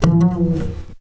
Upright 4 F.wav